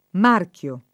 vai all'elenco alfabetico delle voci ingrandisci il carattere 100% rimpicciolisci il carattere stampa invia tramite posta elettronica codividi su Facebook marchio [ m # rk L o ] (antiq. marco [ m # rko ]) s. m. («contrassegno»); pl. ‑chi